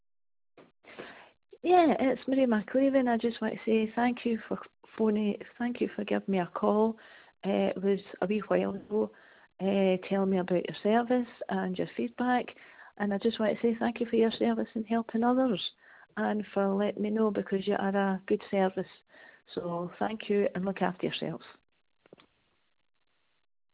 Positive-feedback-from-client.wav